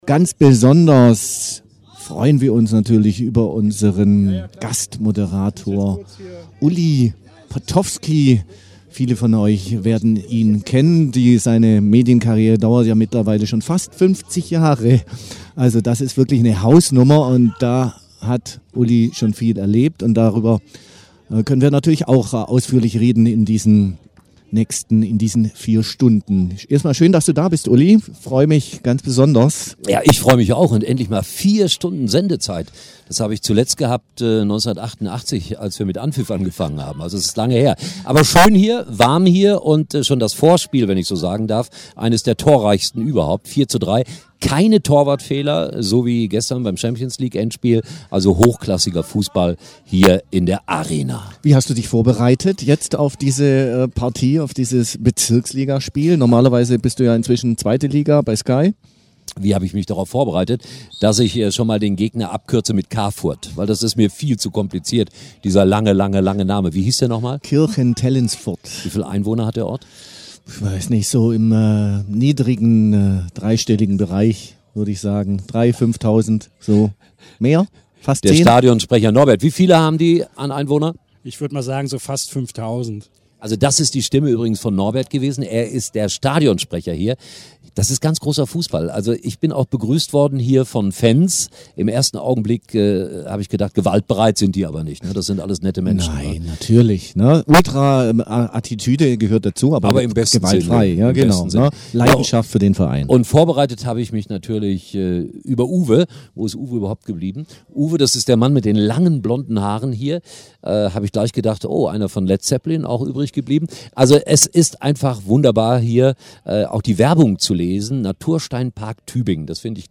Bezirksligameister SSC Tübingen :: Ulli Potofski kommentiert und erzählt